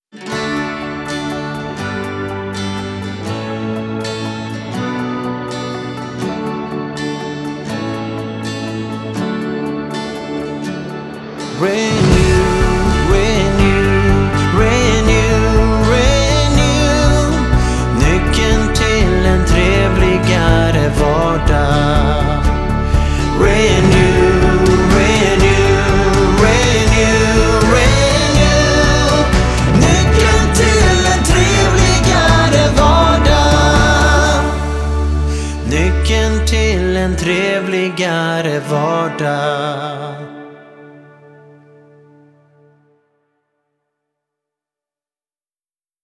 SOUND IDENTITY